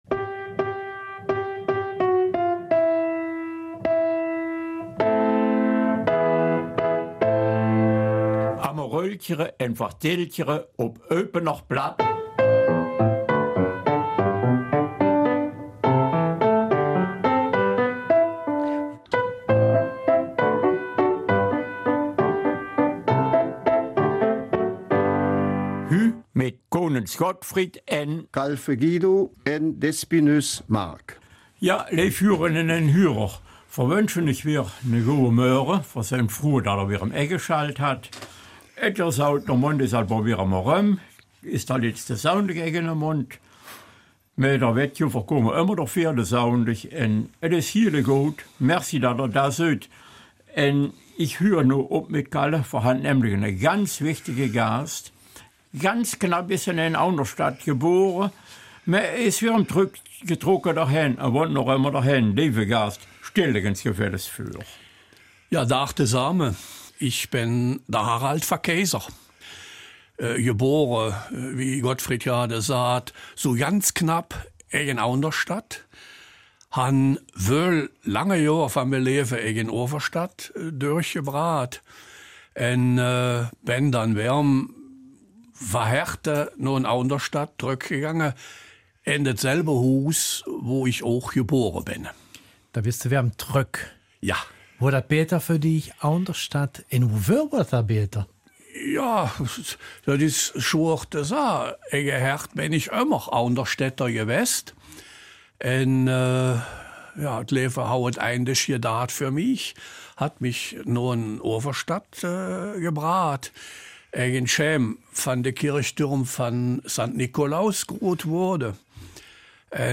Eupener Mundart: Des Kaisers neue Kleider
Unser Studiogast, dessen Name ich noch nicht verraten will, ist ein Großer.